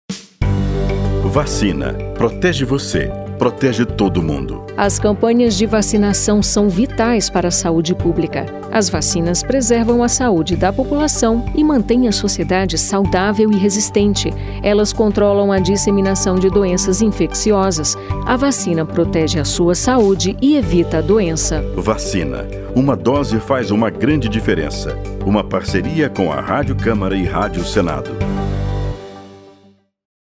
07-spot-imunizao-camara-com-senado.mp3